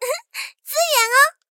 SU-76获得资源语音.OGG